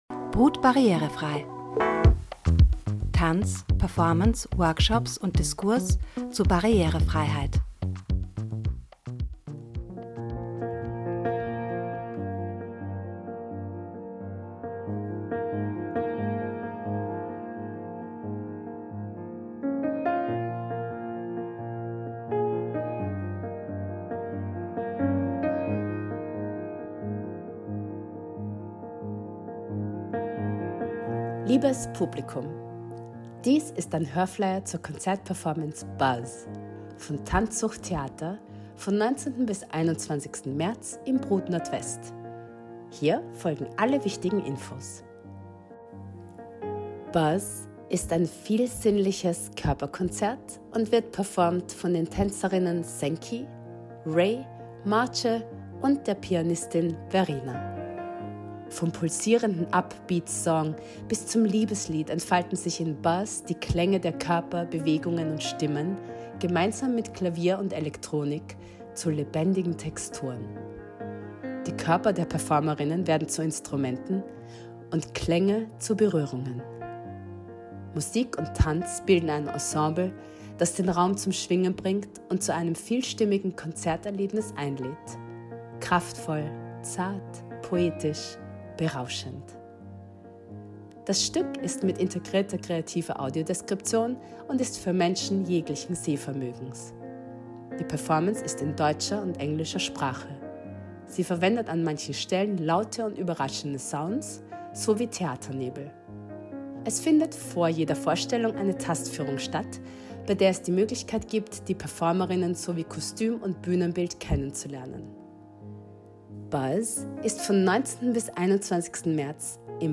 Hörflyer mit Informationen zum Stück und zur Barrierefreiheit: